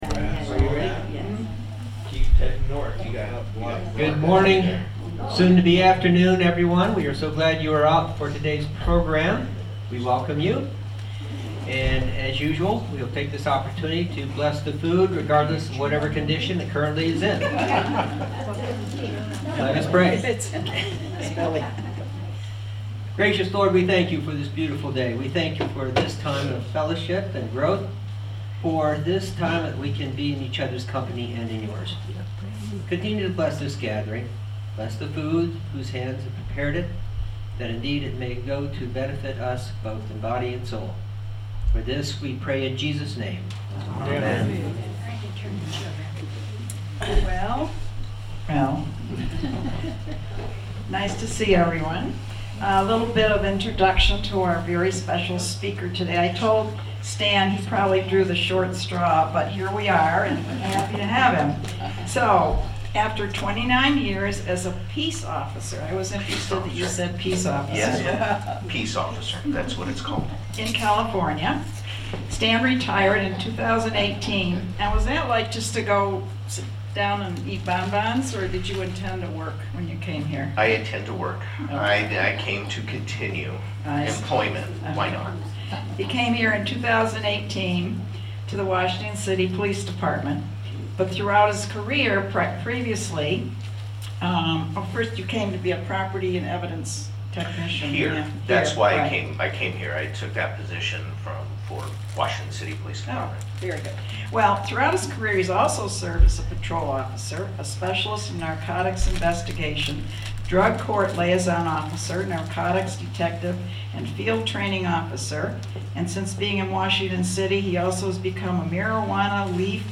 At our Lunch and Learn program on Wednesday, March 19th, we learned about what steps we can take to protect ourselves from those intent on doing us harm.